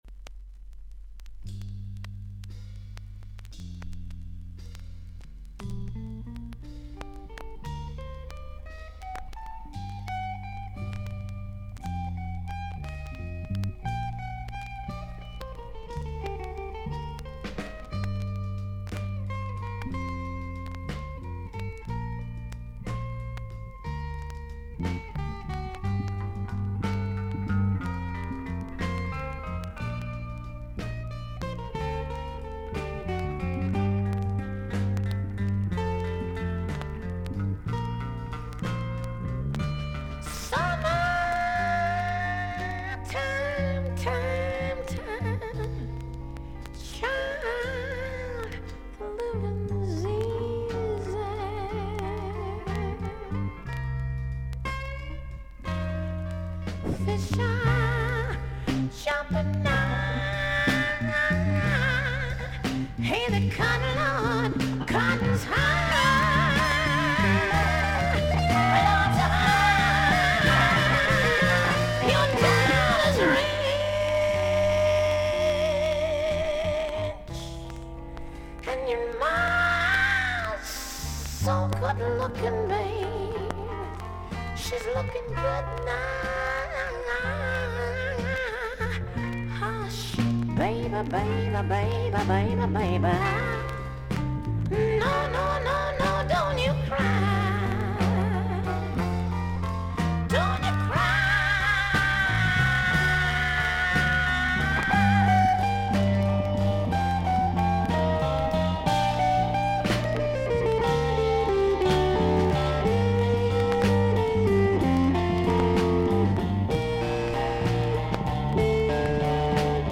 所々に少々軽いパチノイズの箇所あり。少々サーフィス・ノイズあり。クリアな音です。